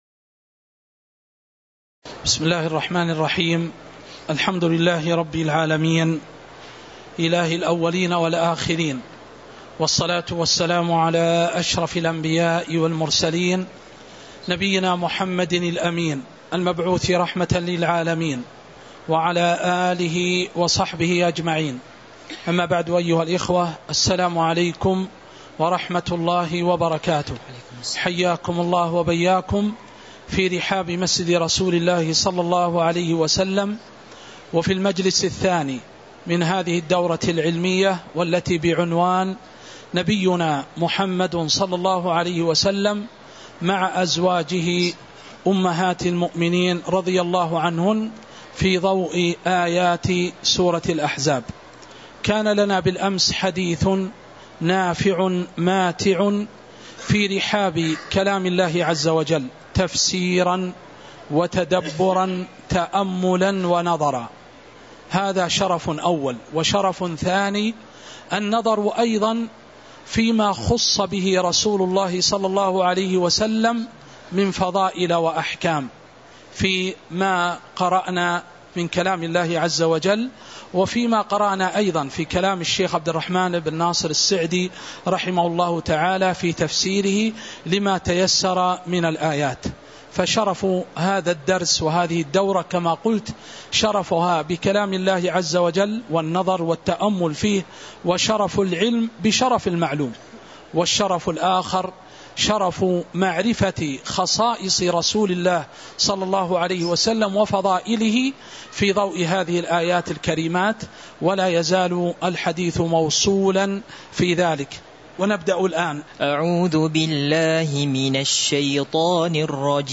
تاريخ النشر ٢١ ربيع الثاني ١٤٤٥ هـ المكان: المسجد النبوي الشيخ